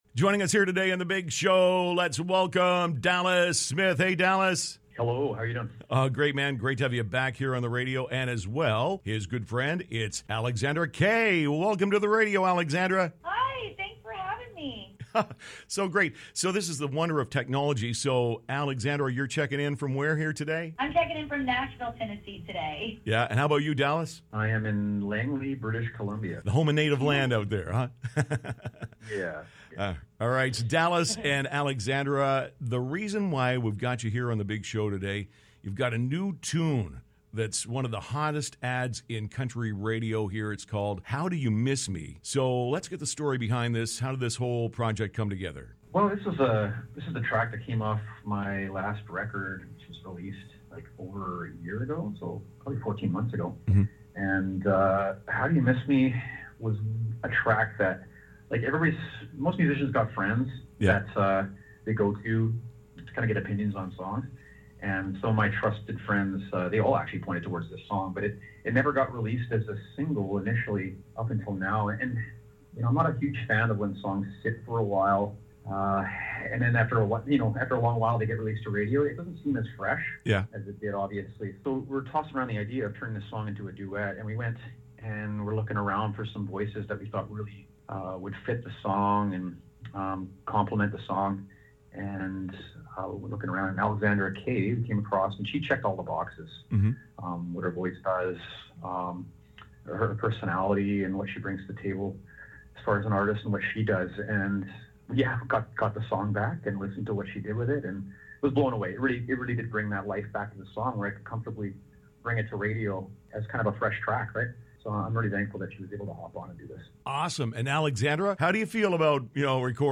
Dallas Smith and Alexandra Kay called me to chat about their new duet called “How Do You Miss Me?”